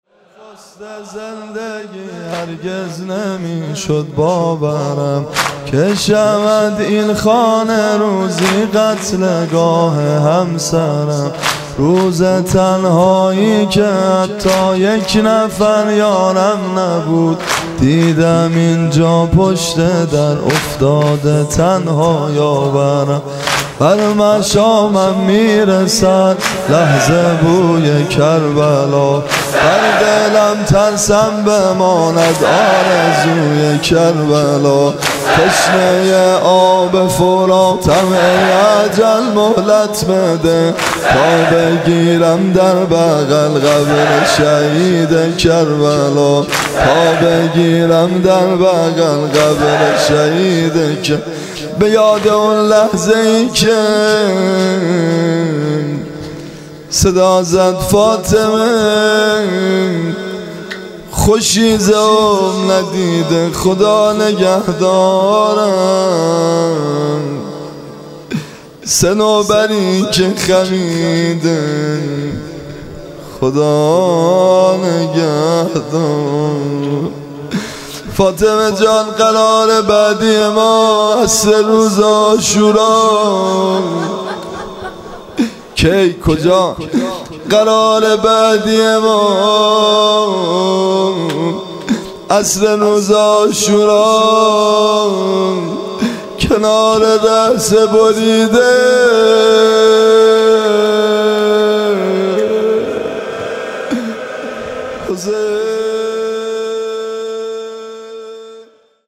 مناسبت : شهادت حضرت فاطمه زهرا سلام‌الله‌علیها
قالب : واحدشور